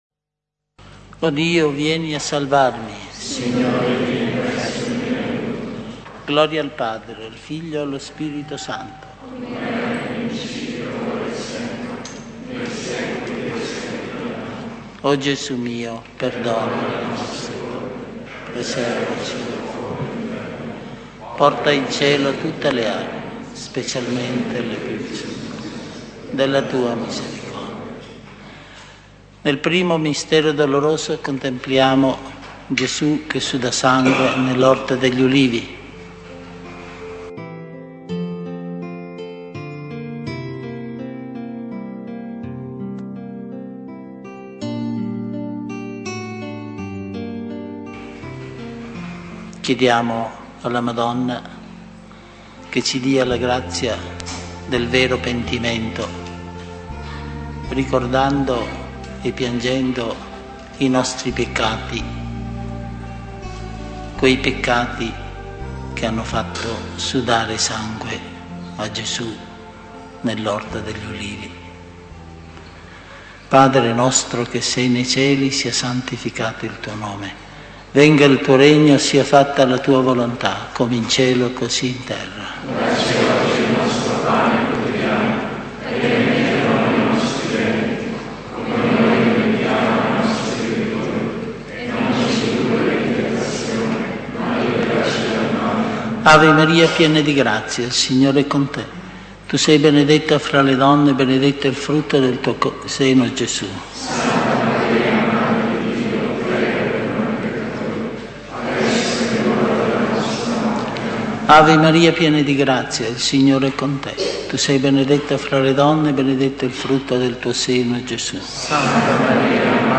Genere: Rosario.